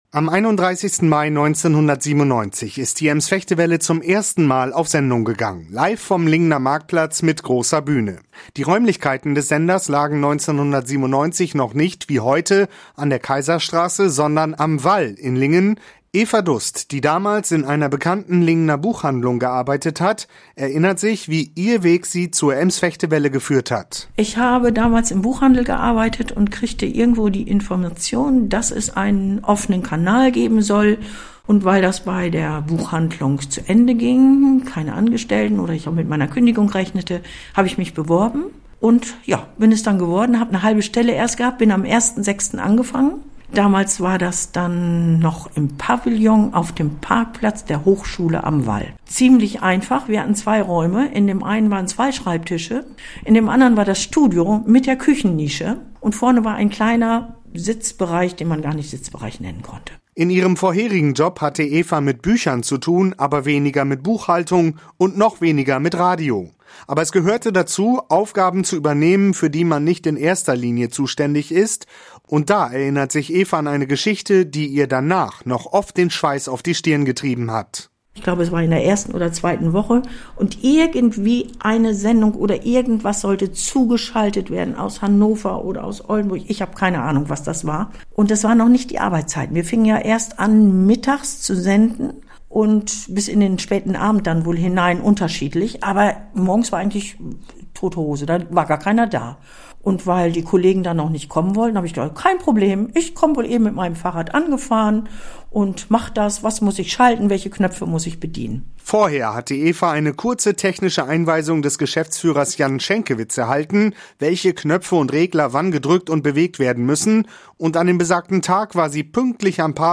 Meistens sind es Angestellte, Wegbegleiter oder Journalisten, die uns interessante, spannende oder informative Geschichten erzählt haben. Heute blicken wir noch einmal auf die Anfangsjahre des Senders zurück. 1997 hat die ems-vechte-welle mit einem ganz kleinen Team begonnen.